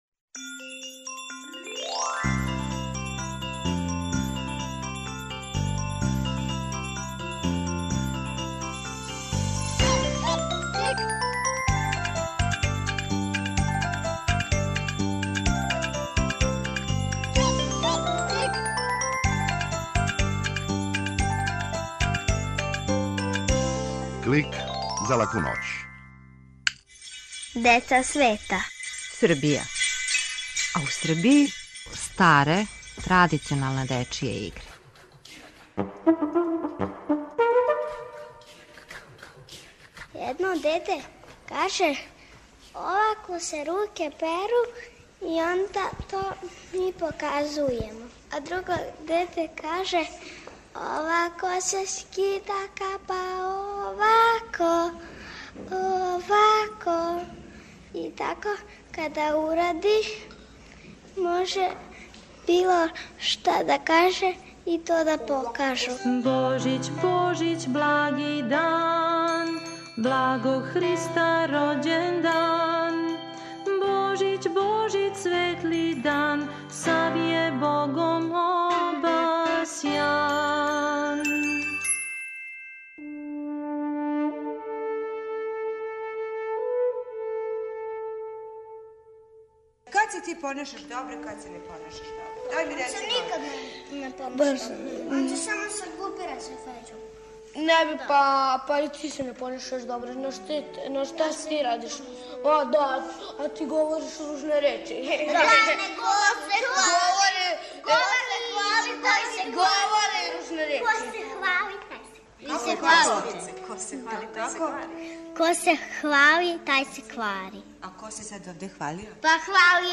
Клик је кратка емисија за децу, забавног и едукативног садржаја. Сваке седмице наши најмлађи могу чути причу о деци света, причу из шуме, музичку упознавалицу, митолошки лексикон и азбуку звука. Aко желите да Клик снимите на CD или рачунар, једном недељно ,на овој локацији можете пронаћи компилацију емисија из претходне недеље, које су одвојене кратким паузама.